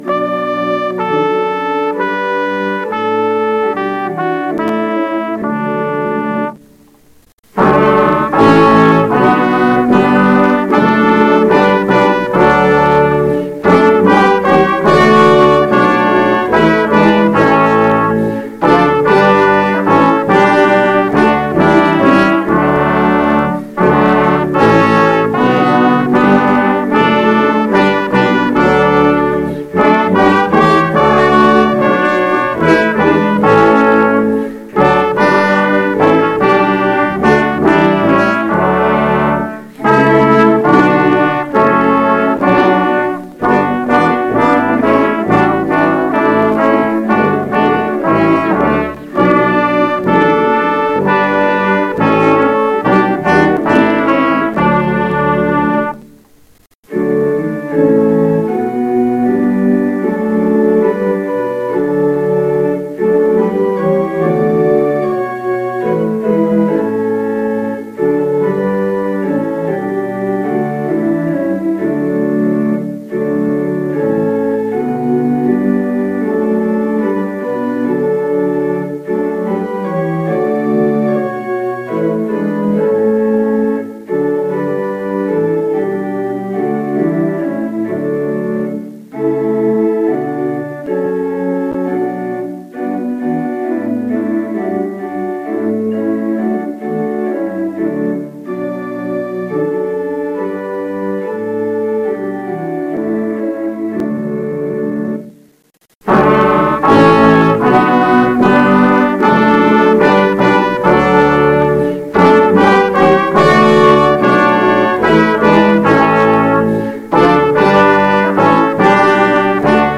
Music on the Sunday of Pentecost
organist
elw-786-organbrass.mp3